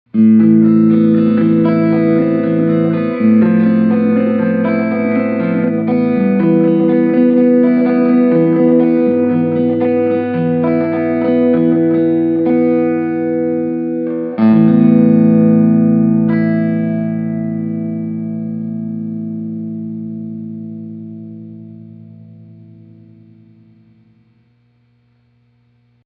Here’s the same track, but this time, I’ve textured it with reverb, delay, compression and EQ.
Had the compression make-up gain a little high, so there’s a tiny bit of clipping, but what I was able to get was a super-rich and full sound.